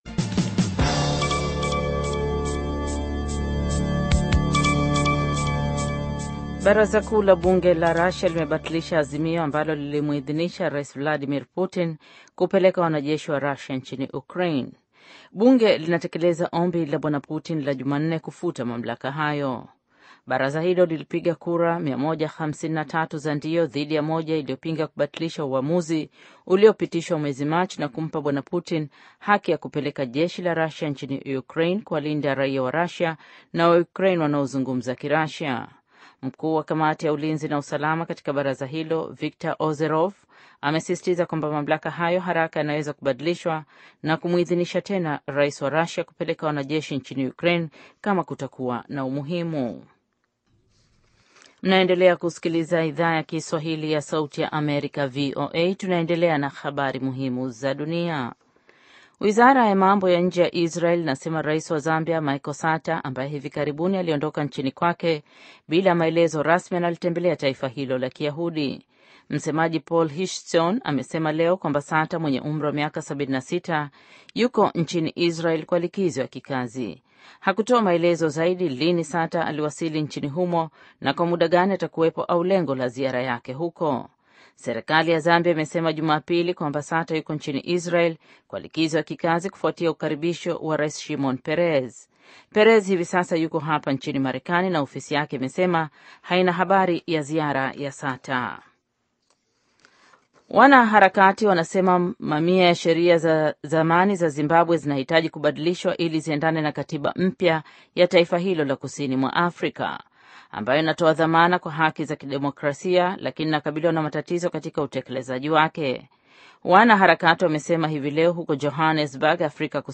Taarifa ya Habari VOA Swahili - 4:58